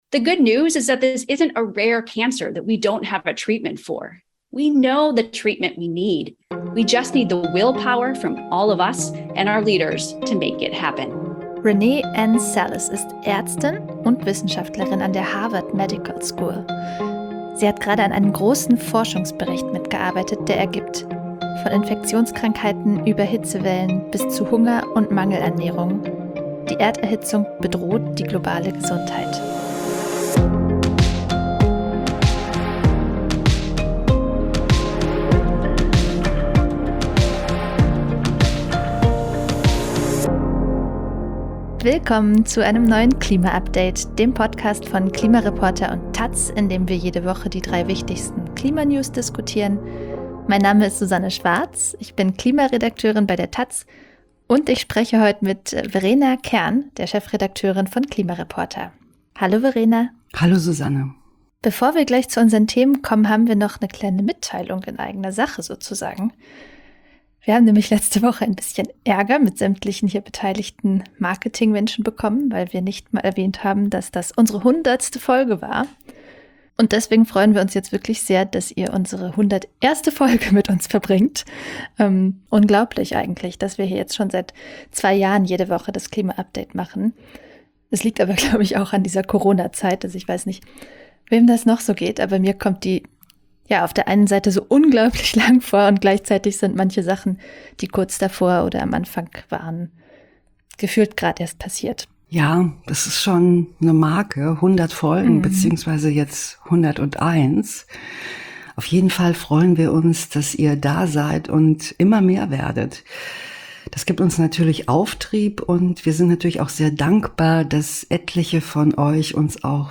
Im klima update° besprechen Journalistinnen vom Online-Magazin klimareporter° und von der Tageszeitung taz jeden Freitag die wichtigsten Klima-Nachrichten der Woche.